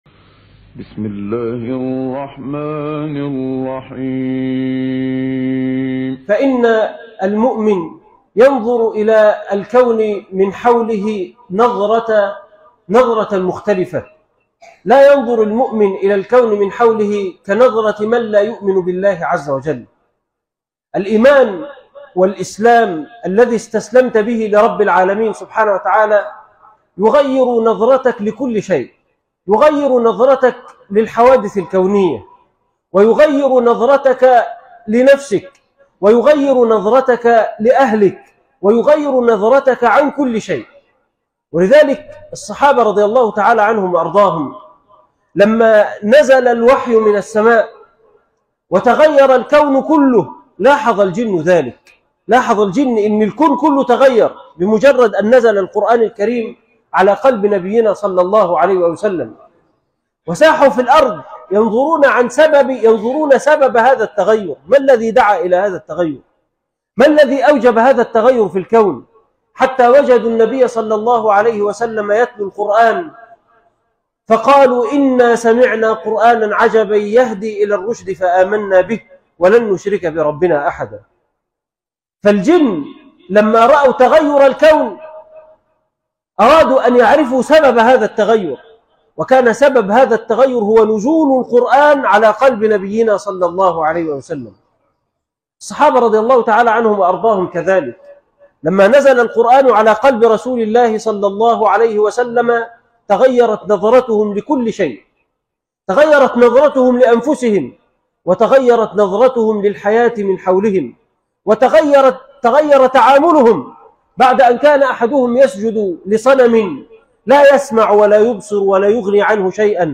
عنوان المادة نظرة المؤمن للكون: مشاهد من آيات الله - خطبة جمعة تاريخ التحميل الأثنين 21 يوليو 2025 مـ حجم المادة 30.16 ميجا بايت عدد الزيارات 142 زيارة عدد مرات الحفظ 60 مرة إستماع المادة حفظ المادة اضف تعليقك أرسل لصديق